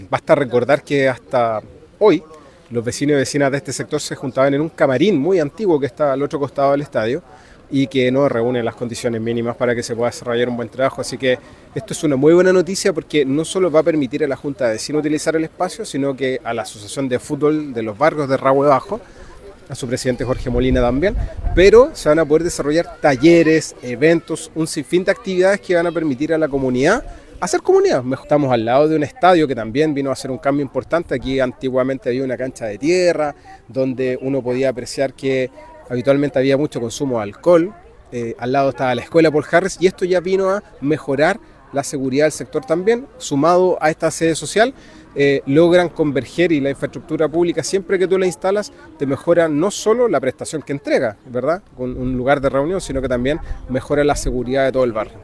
El concejal Miguel Arredondo también valoró la iniciativa, señalando que este proyecto complementa las mejoras ya realizadas en el complejo deportivo del sector, las cuales no solo embellecieron el lugar, sino que también ayudaron a eliminar focos de inseguridad, contribuyendo al bienestar de la comunidad.